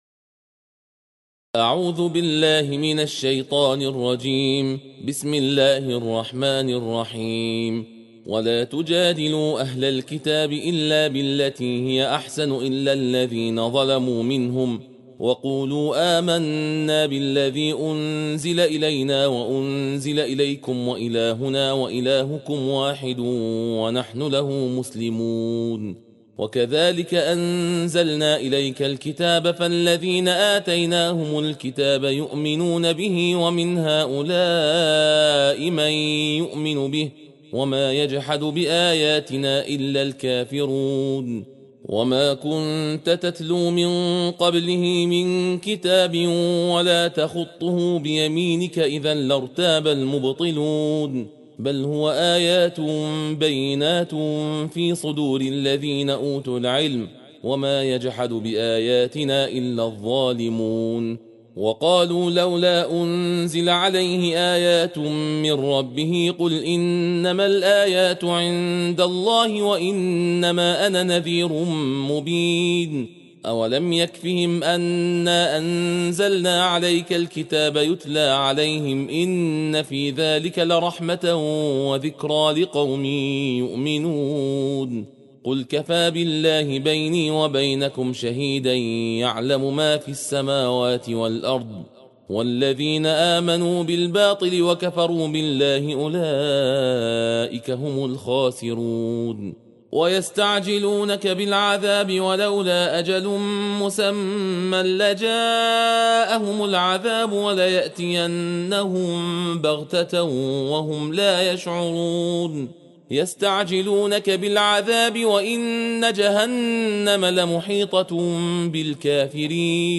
جزء بیست و یکم قرآن صوتی تندخوانی با متن و ترجمه درشت جز 21 - ستاره